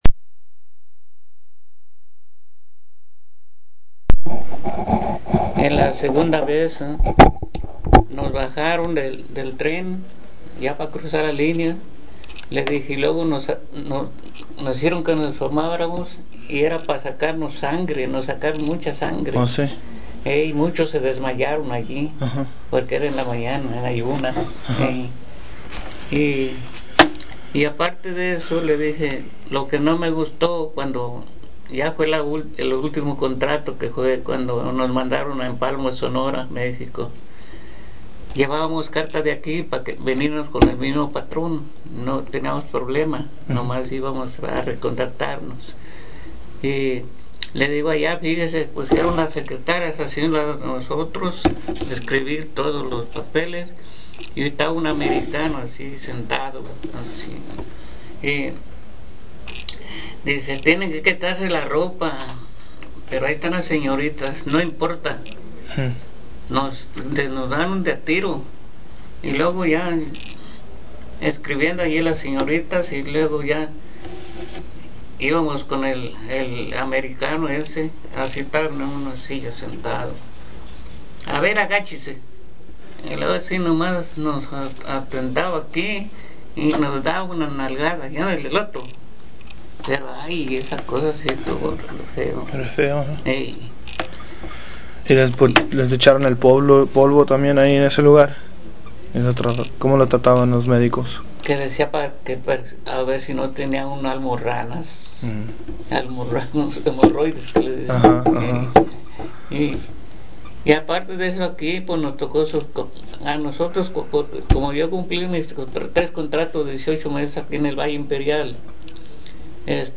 Location Coachella, CA Original Format Mini disc